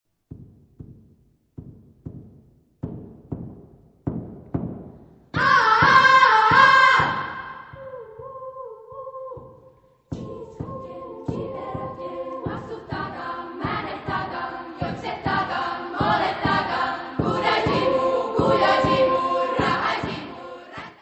: stereo; 12 cm
Music Category/Genre:  World and Traditional Music